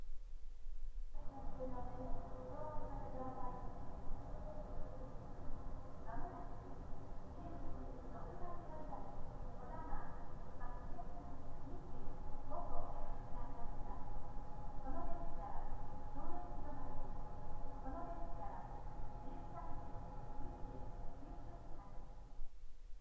具体的な方法として、「JRの構内（アナウンスあり）の音」のフリー音源をオーディオシステムで大音量で再生して、疑似的にJRの構内の環境を作ります。
バイノーラル録音マイクを耳に取り付け、その上からヘッドホンM4、M3を耳に装着してノイズキャンセリングをオンにします。
この状態で聴こえる音を録音しました
バイノーラルマイク　ローランド CS-10EM
マイクを耳に着けた上から、ヘッドホンを装着した。この状態でオーディオスピーカーから鳴っている駅の環境音がどのようにキャンセルされるかを録音した。
WH-1000XM3 ノイキャンあり